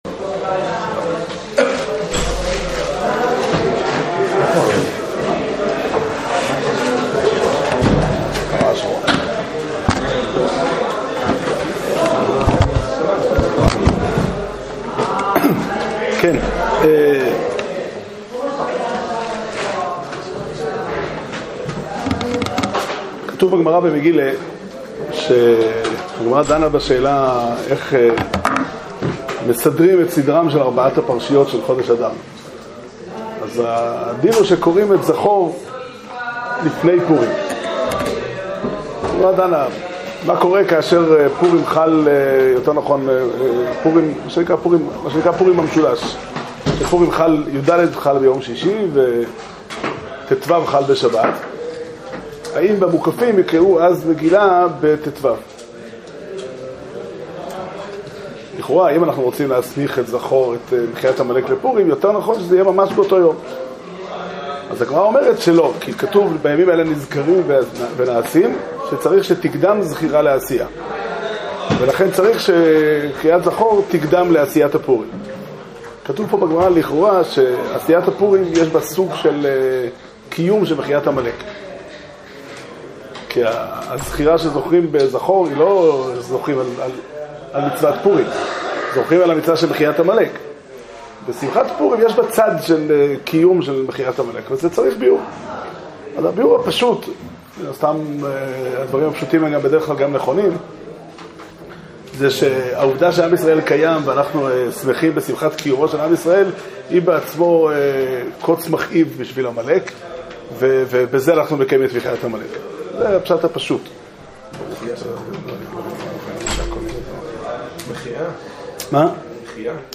שיעור שנמסר בבית המדרש פתחי עולם